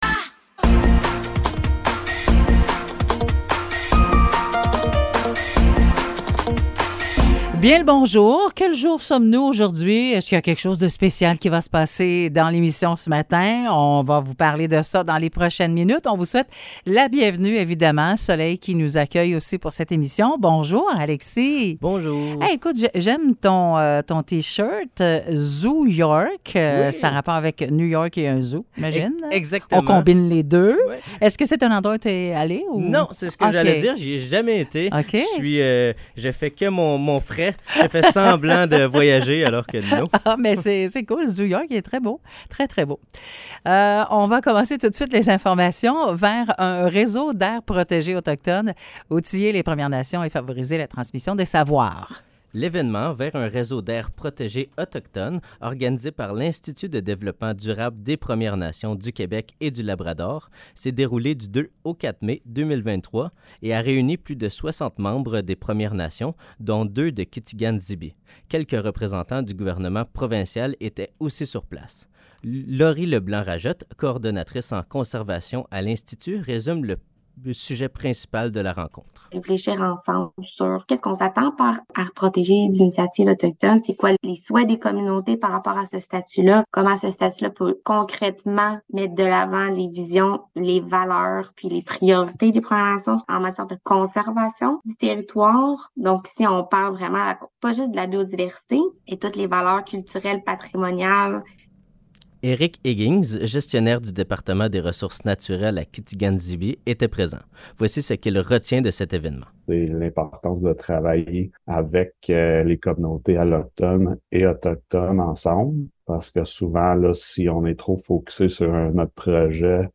Nouvelles locales - 18 mai 2023 - 9 h